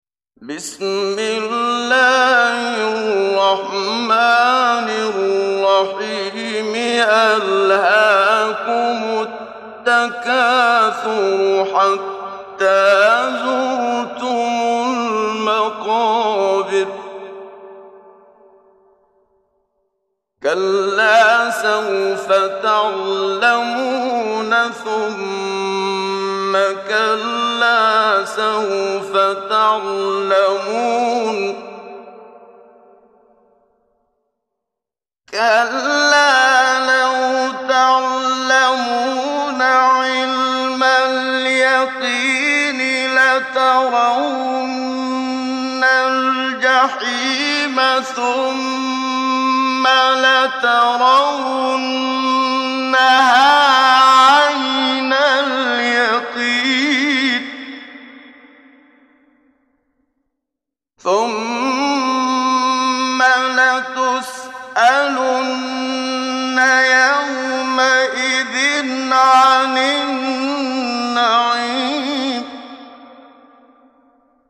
محمد صديق المنشاوي – تجويد